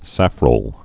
(săfrōl)